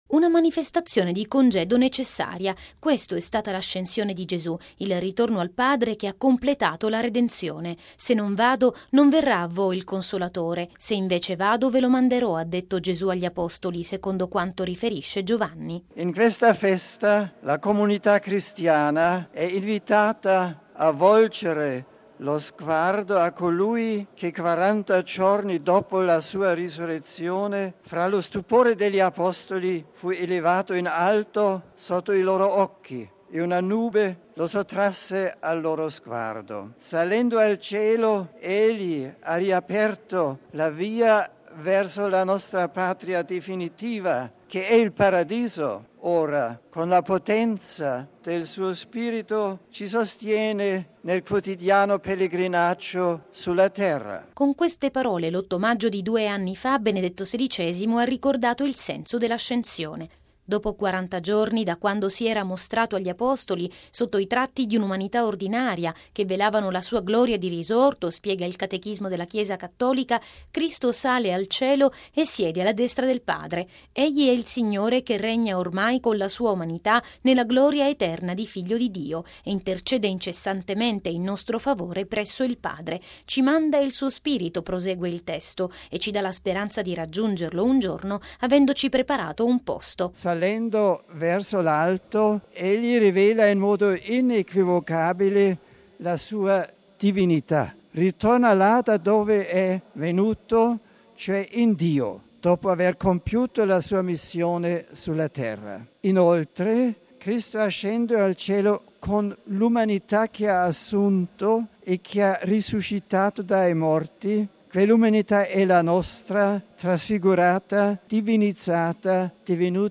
Logo 50Radiogiornale Radio Vaticana